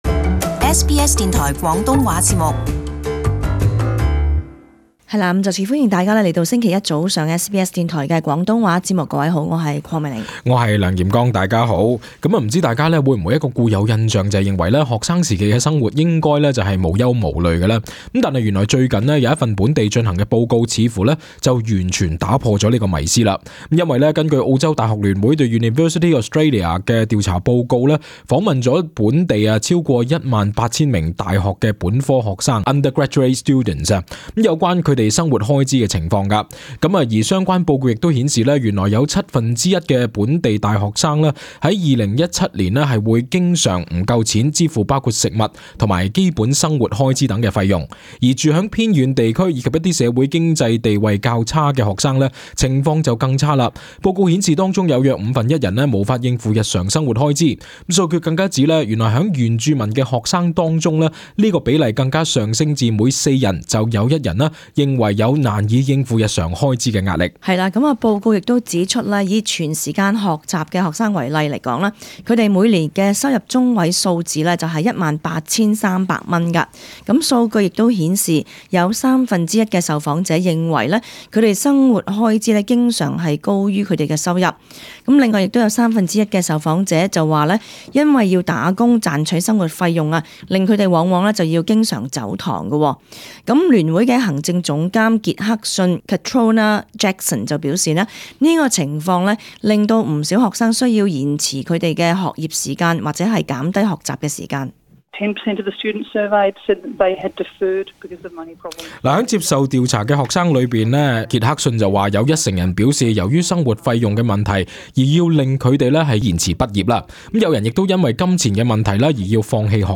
【时事报导】报告指七份一澳洲学生去年生活结据